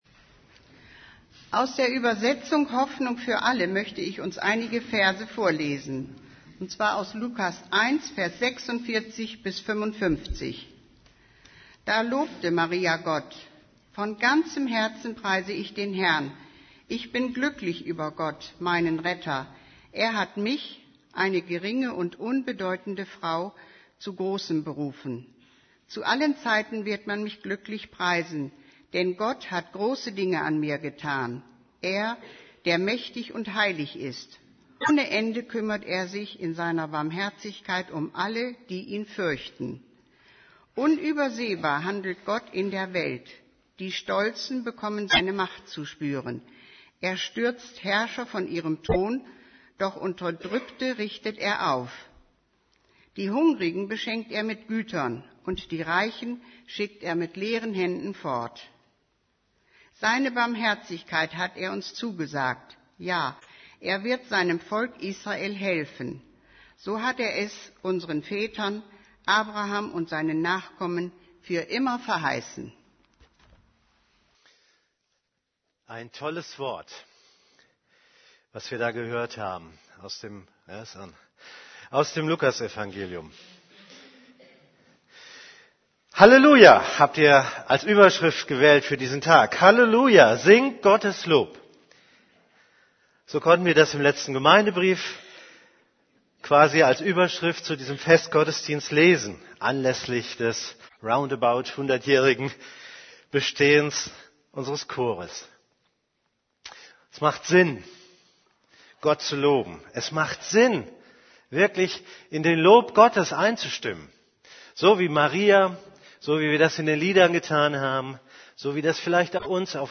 Lobt Gott - Chorgottesdienst 100 Jahre Gemeindechor